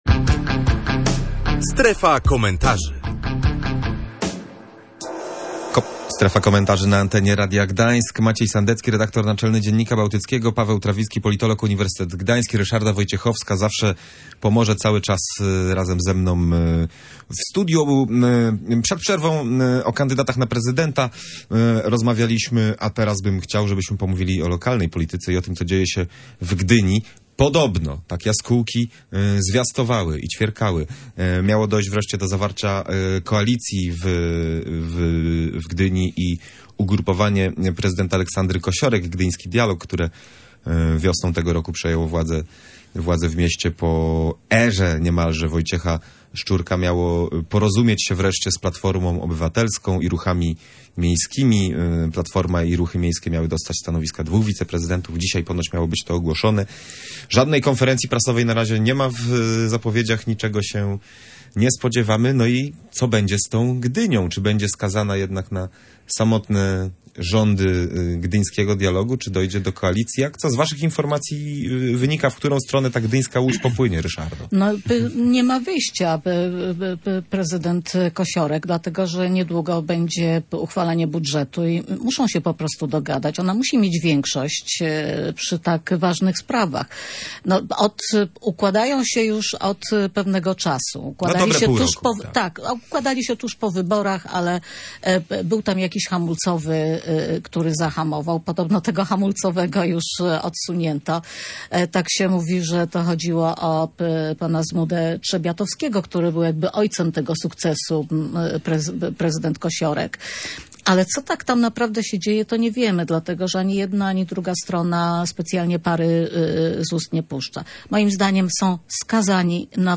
Ostatnia audycja "Radiowo-Naukowo" była poświęcona cukrzycy typu pierwszego. W rozmowie